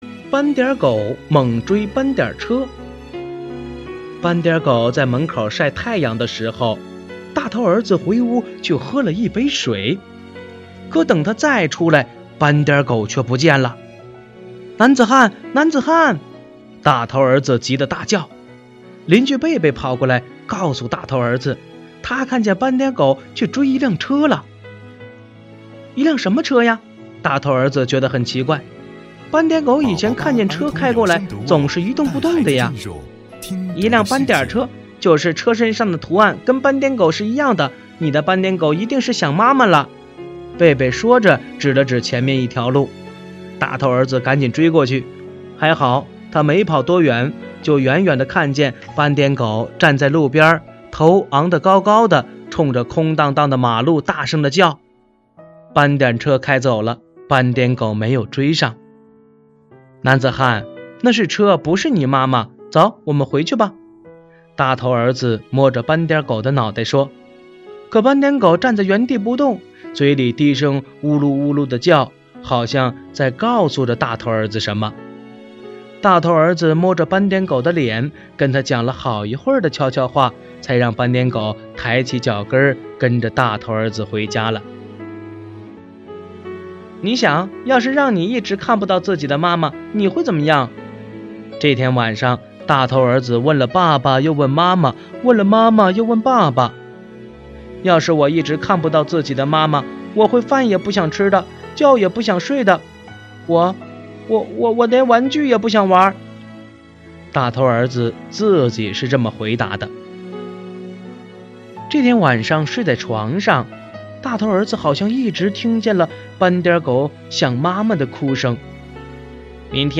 首页>mp3 > 儿童故事 > 10斑点狗猛追斑点车（大头儿子和斑点狗）